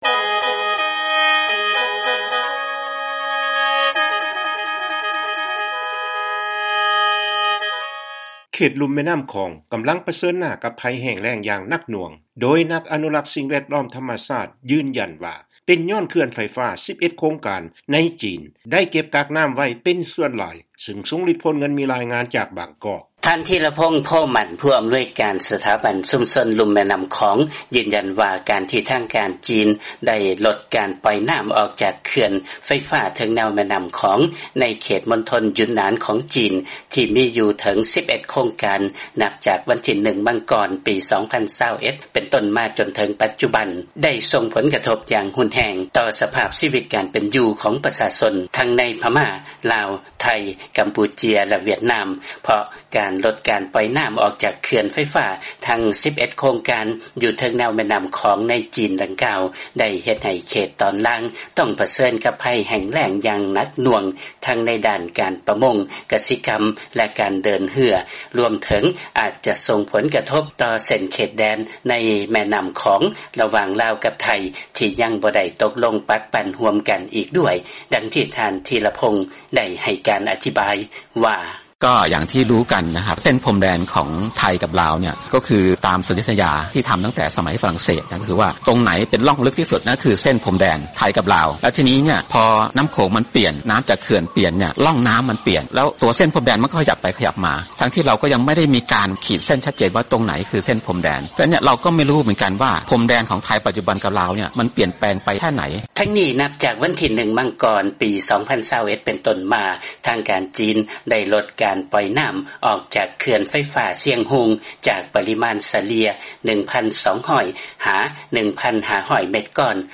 ຟັງລາຍງານ ເຂດລຸ່ມແມ່ນໍ້າຂອງ ກຳລັງປະເຊີນກັບ ໄພແຫ້ງແລ້ງ ຢ່າງໜັກໜ່ວງ